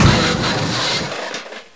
BikeCrash_01.mp3